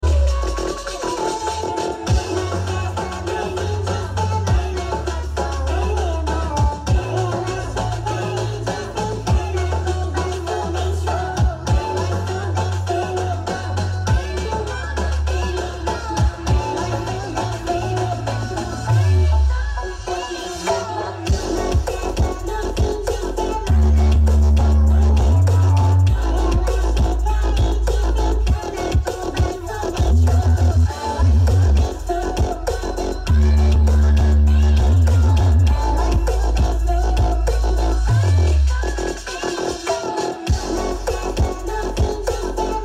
Brewog Audio Ceksound Karnaval Peniwen Sound Effects Free Download.